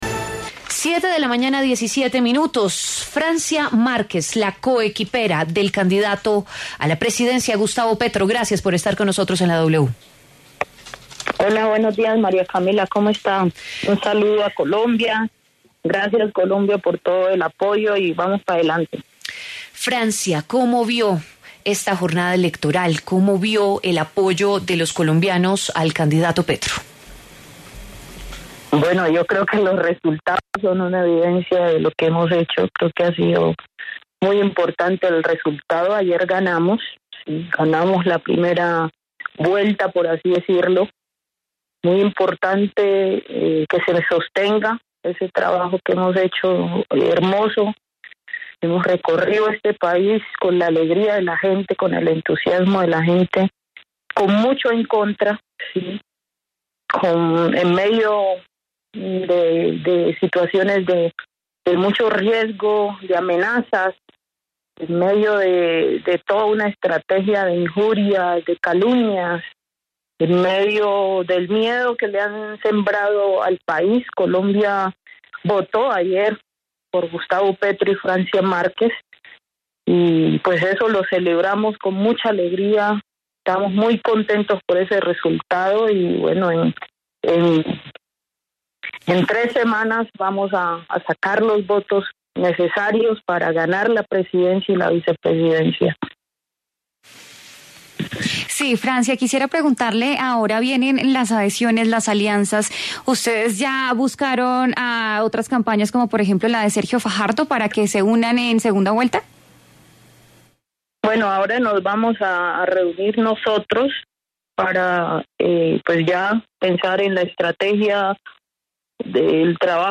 Francia Márquez, fórmula vicepresidencial de Gustavo Petro, conversó con La W para hacer un balance sobre los resultados de las elecciones del pasado 29 de mayo y la nueva etapa que enfrenta la contienda electoral.